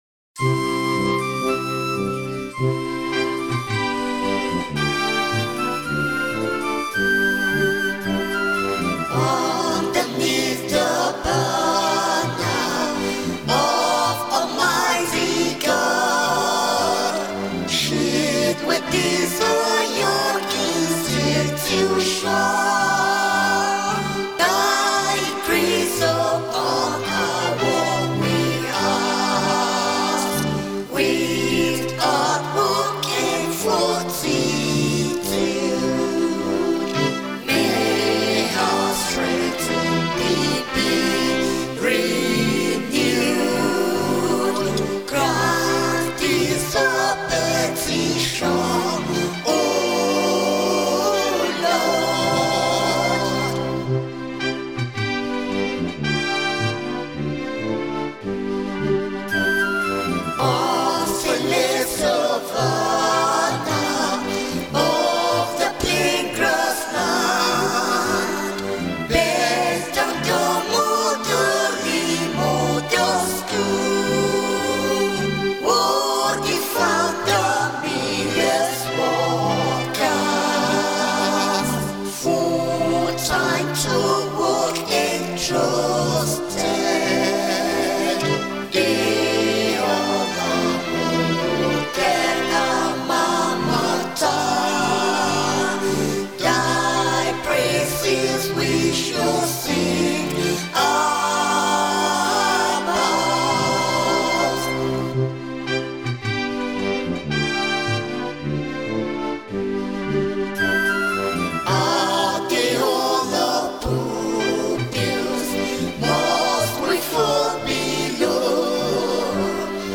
School Anthem